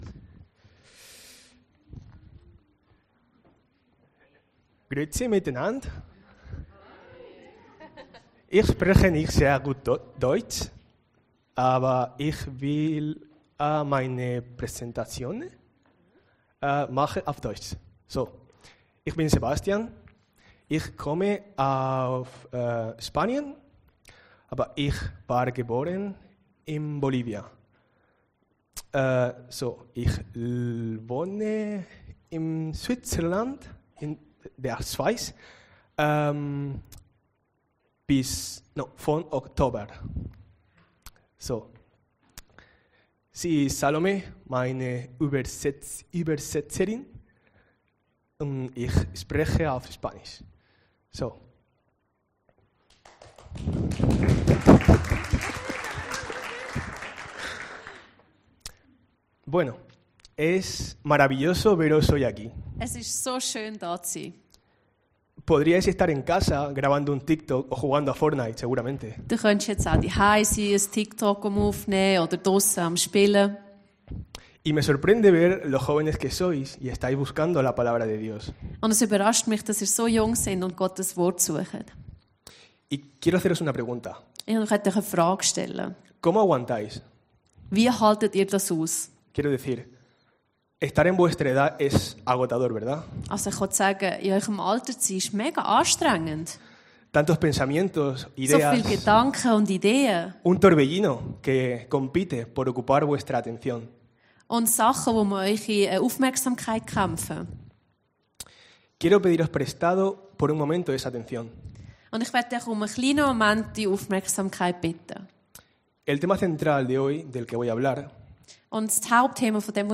Predigten Heilsarmee Aargau Süd – The Good News - für mein Umfeld